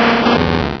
Cri de Limagma dans Pokémon Rubis et Saphir.